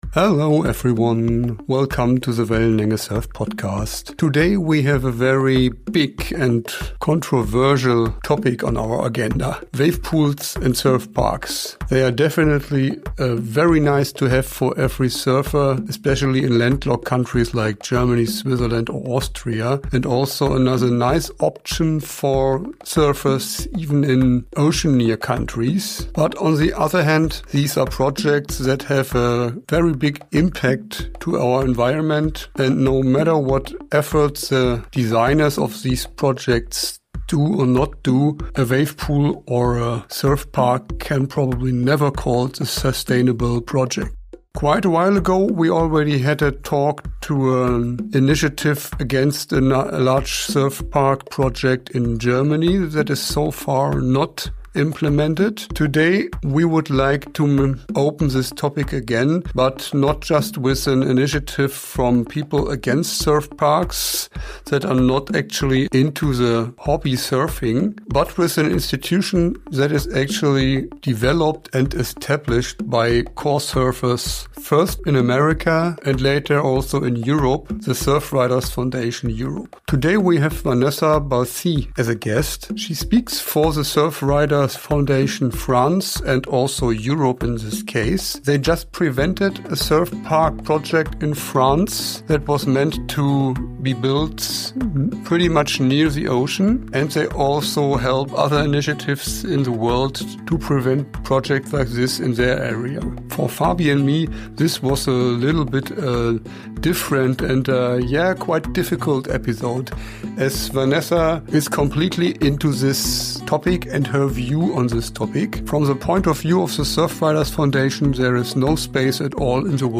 In our conversation, we explore the pros and cons of wavepools, and look at meaningful alternatives to cope with surf withdrawal — without compromising our connection to nature.